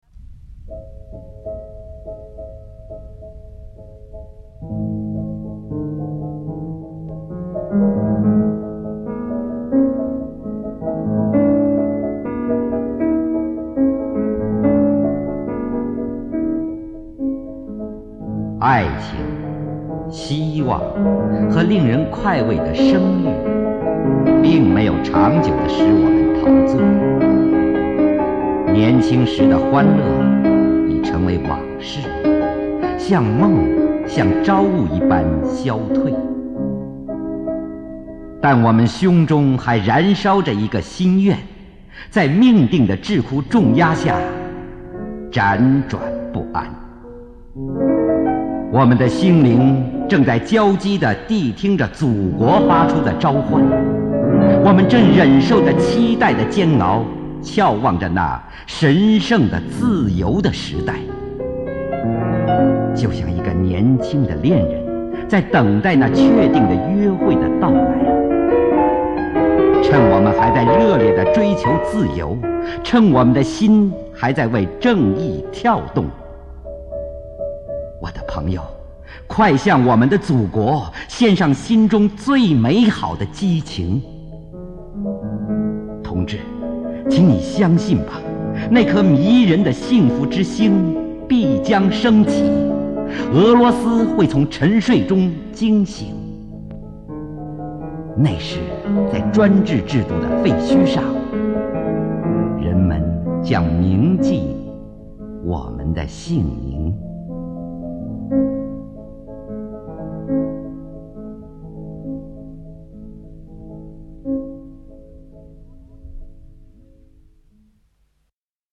普希金诗配乐朗诵
（由录音带转录）